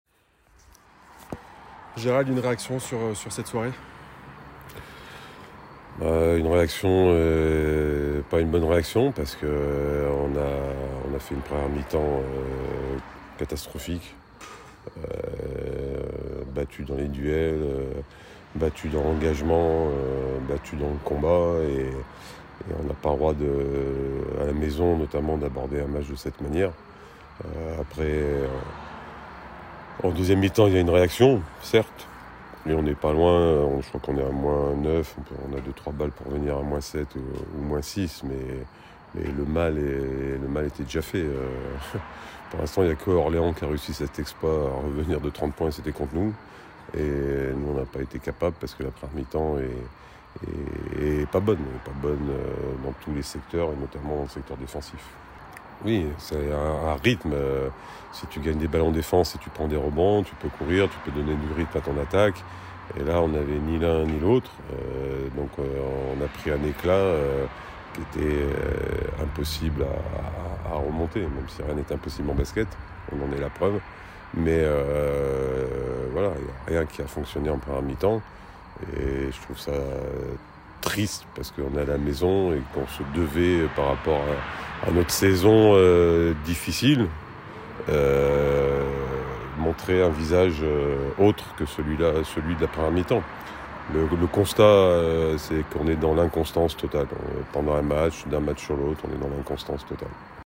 Betclic Elite #32 – Les réactions - JL Bourg Basket